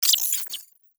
Robotic Game Notification 4.wav